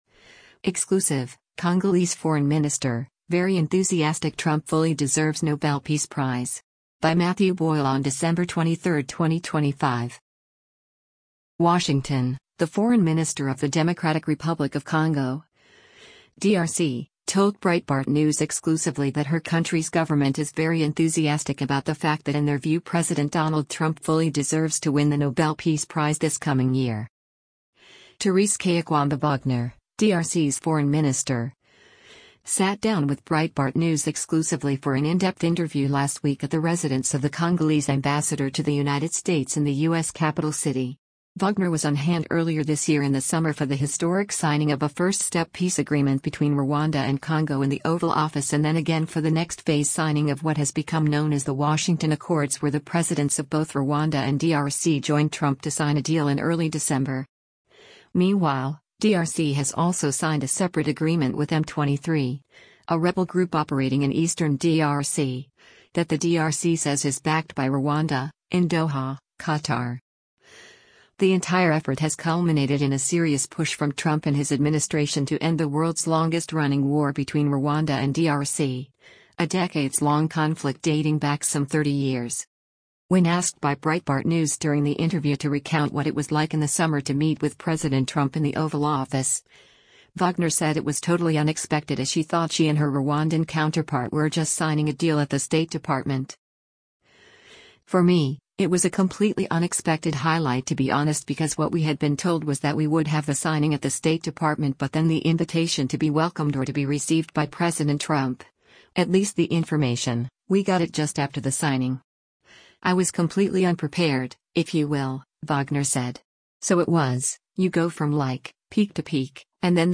Thérèse Kayikwamba Wagner, DRC’s foreign minister, sat down with Breitbart News exclusively for an in-depth interview last week at the residence of the Congolese ambassador to the United States in the U.S. capital city.